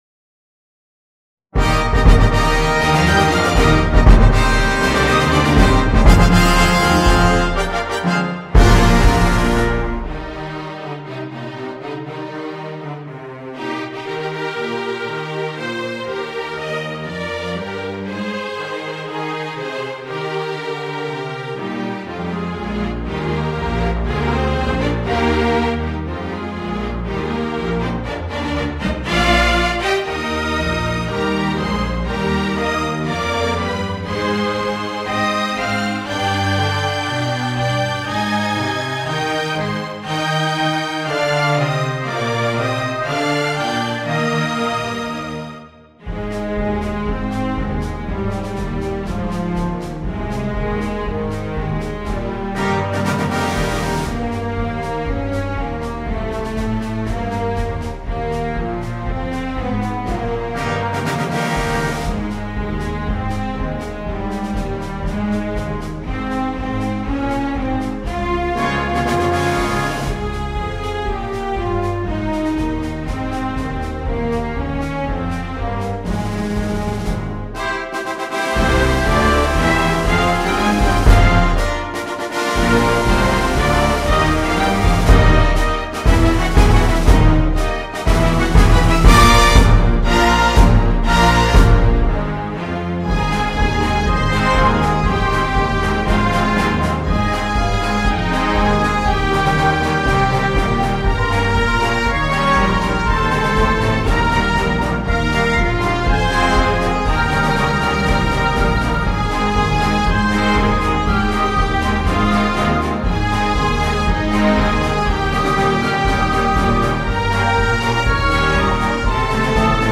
The-Pennsylvania-March-for-Orchestra1-1.mp3